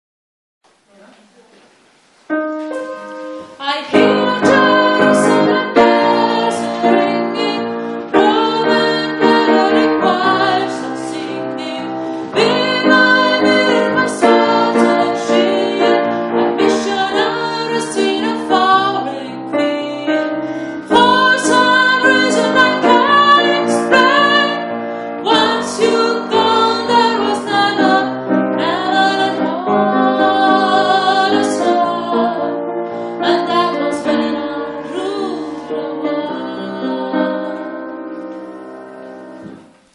Viva la Vida – Sopran-Refrain
Viva-la-Vida-Refrain-Sopran.mp3.mp3